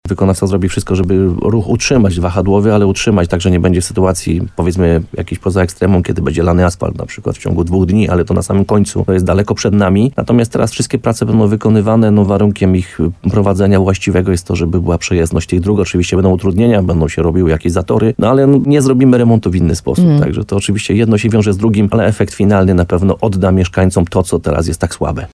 Burmistrz Krynicy-Zdroju, Piotr Ryba przekonuje, że w trakcie remontu ruch w kierunku Izb, Wysowej czy Brunar zostanie utrzymany.